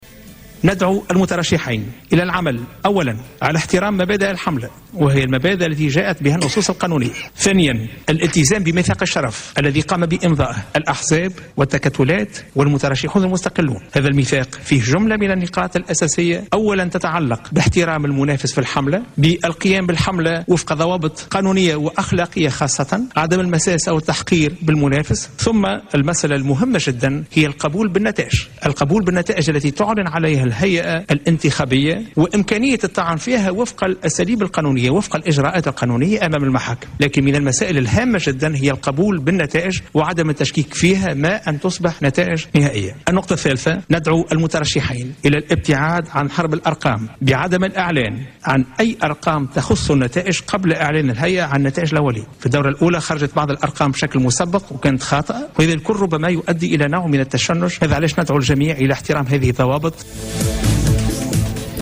كما دعا أيضا خلال ندوة صحفية عقدتها الهيئة اليوم الخميس إلى احترام مبادئ الحملة الانتخابية وضوابطها والابتعاد عن "حرب الأرقام" من خلال عدم الإعلان عن أي أرقام تخص النتائج قبل إعلان الهيئة عن النتائج الأولية،وفق تعبيره.